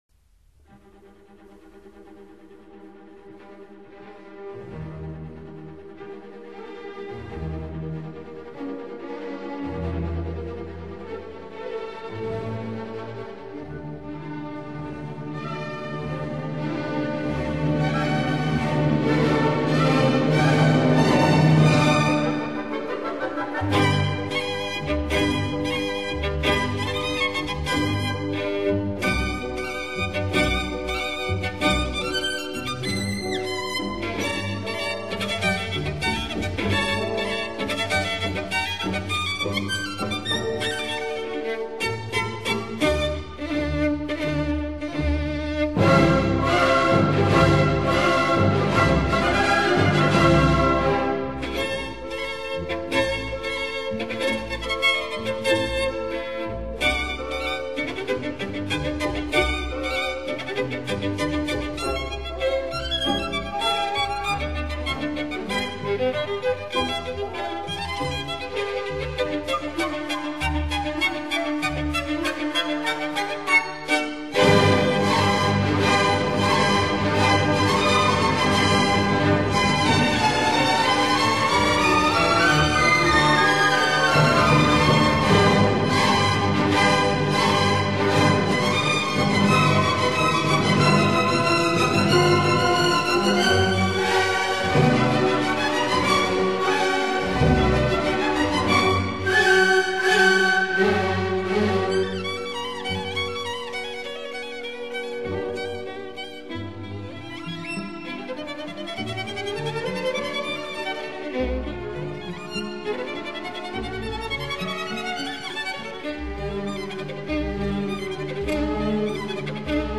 Allegro energico - Presto